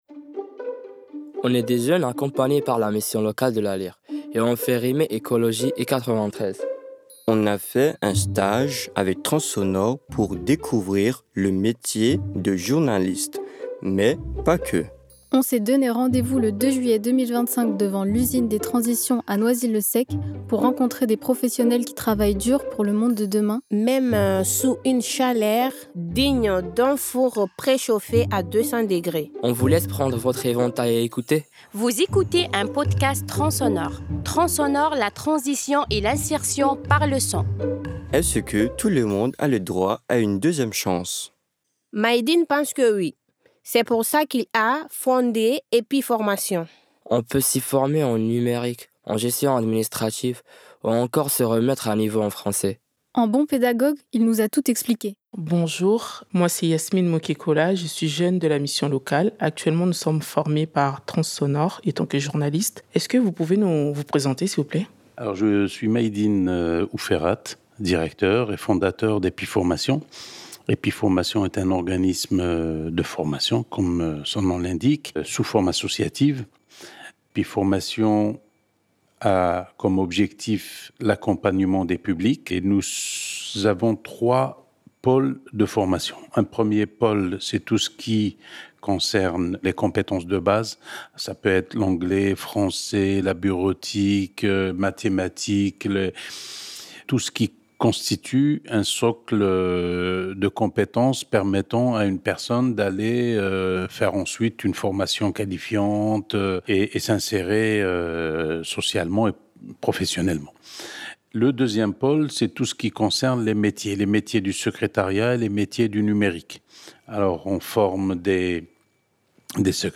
On s’est donné·e rendez-vous le 2 juillet 2025 devant l’Usine des Transitions à Noisy-le-sec, pour rencontrer des professionnel·les qui travaillent dur pour le monde de demain, même sous une chaleur digne d’un four préchauffé à 200°.